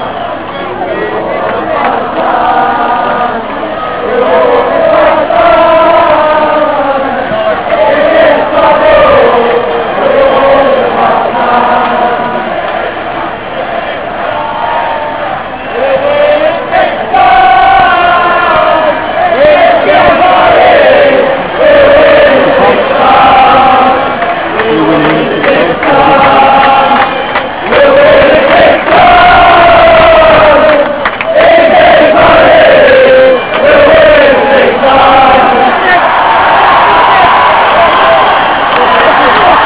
THEME > SUPPORTERS + chants de supporters enregistrés dans les tribunes (fichier mp3